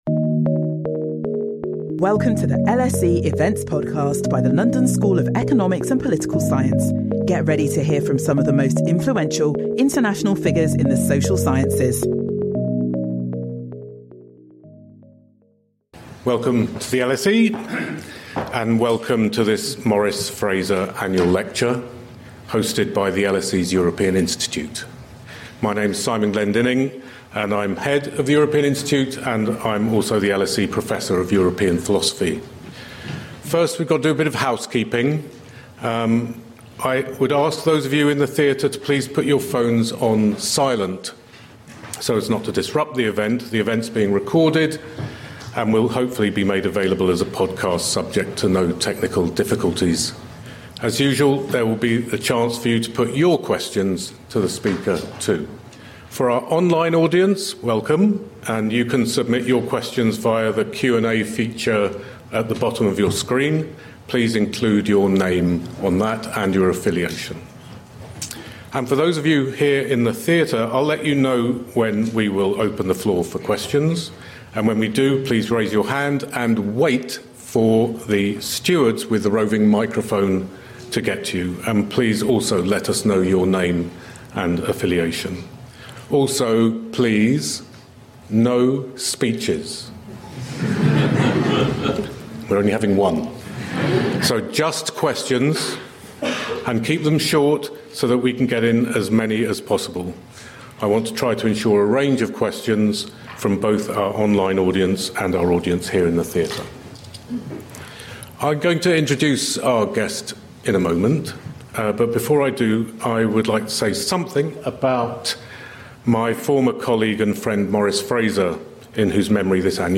Discussing the topic, Britain in a changing world, former British Prime Minister and Leader of the Conservative Party, Sir John Major, delivers this year’s Maurice Fraser Annual Lecture.